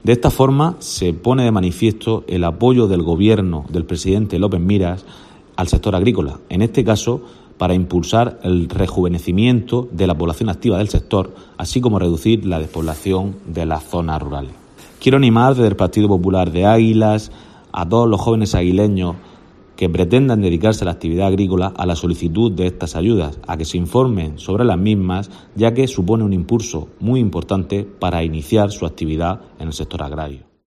Francisco Navarro, edil del PP en Águilas